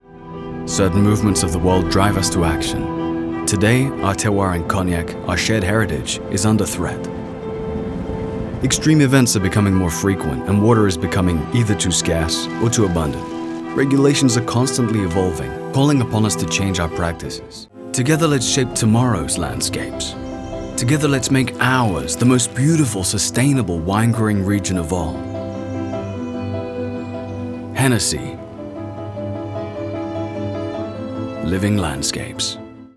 MALE VOICE OVER DEMOS AND EXTRACTS
Commercial Hennessy